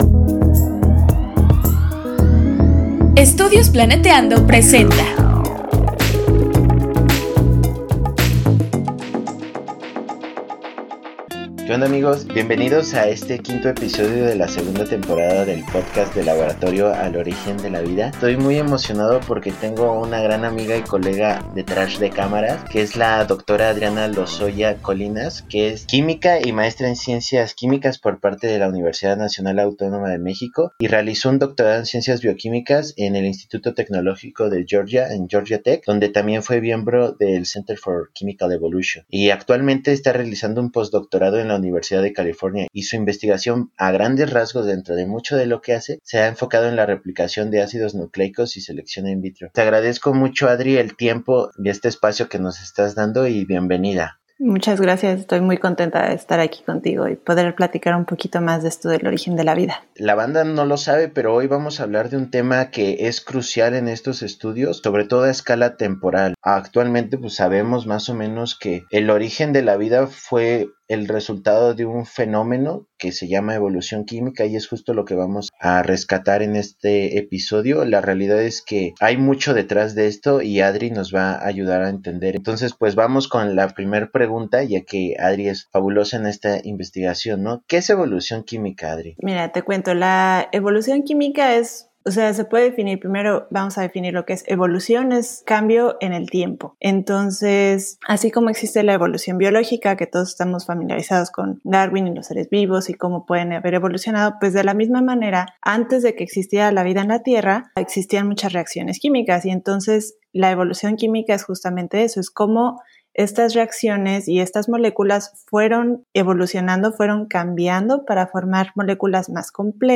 Descubre los procesos que pudieron ocurrir en los primeros millones de años de la Tierra y llevaron al desarrollo de complejidad molecular. Entrevista